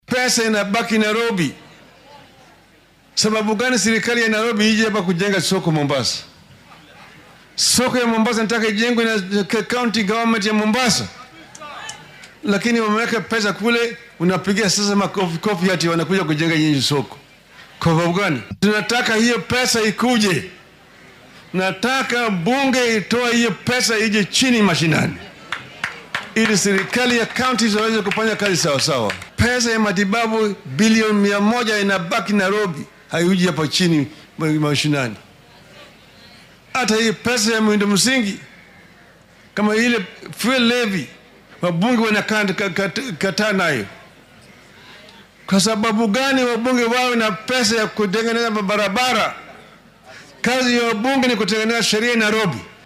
Isagoo ka hadlayay Mombasa,ayuu Odinga sheegay in dhammaan shaqooyinka loogu talagalay dowladaha hoose ay tahay in si buuxda loogu qaybiyo dhaqaalaha loo baahan yahay.